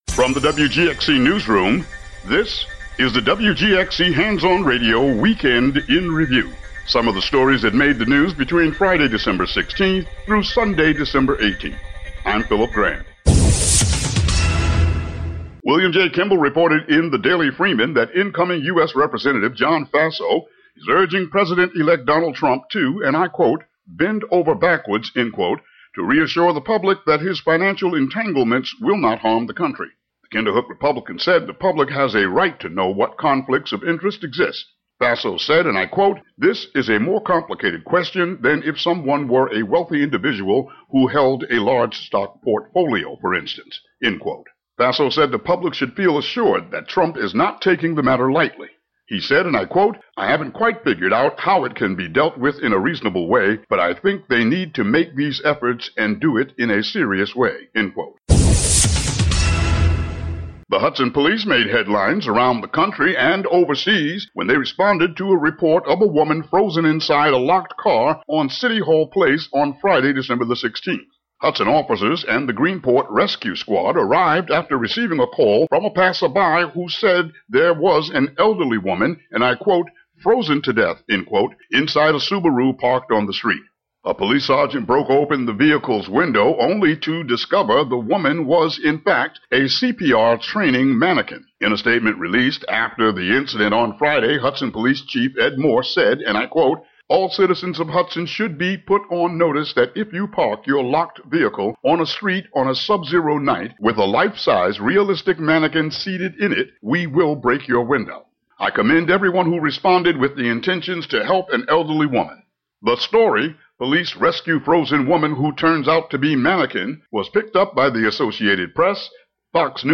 Daily local news for WGXC.